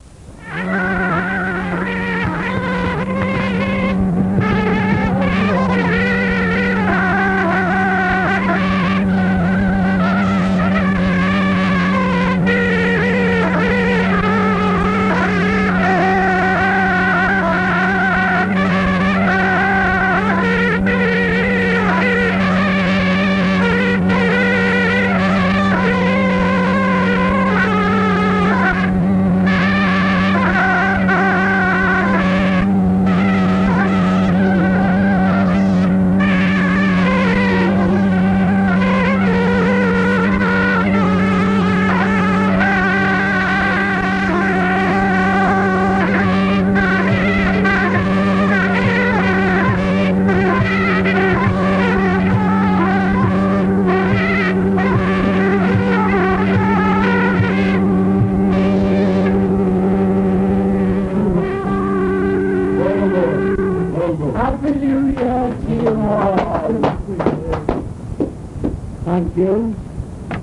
描述：1979年迎接新年的苏格兰风笛的特别糟糕的录音